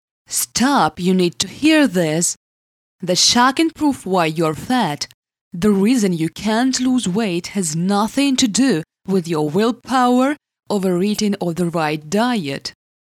Начитка текста на англ.языке Категория: Аудио/видео монтаж